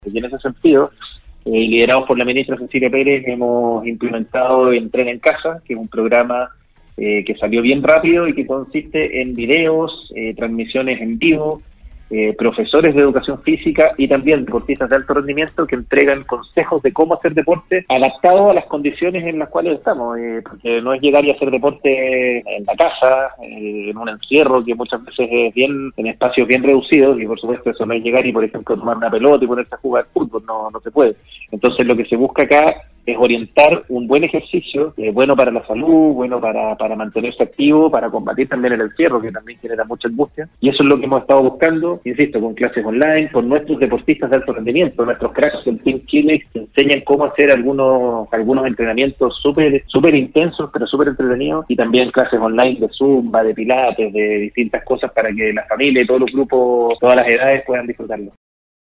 La mañana de este viernes, Andrés Otero, subsecretario del Deporte, sostuvo un contacto telefónico en el programa Al Día de Nostálgica, destacó la importancia del deporte como un beneficio para la salud, el espíritu, y en definitiva, para el bienestar de las personas.